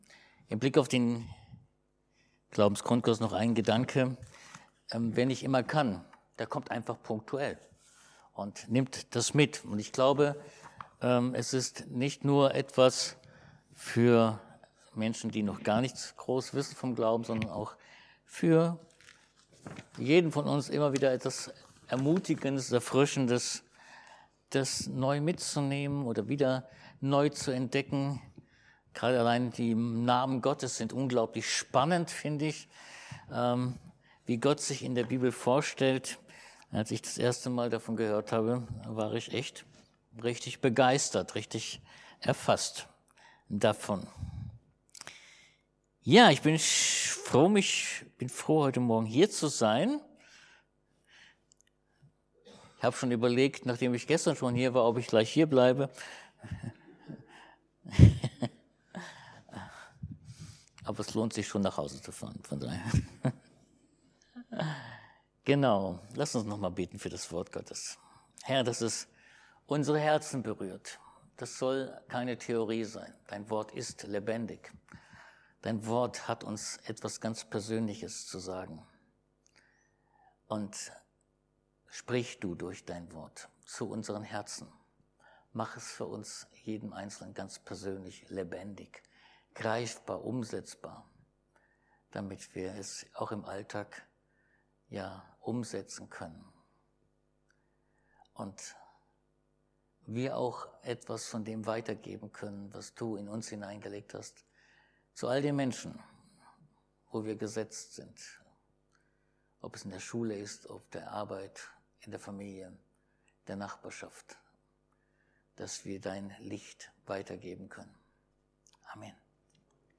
Kor 5,17; MT 10,29-30; Gal 5,22 Dienstart: Predigt Gott möchte uns begegnen, Neues in uns wirken!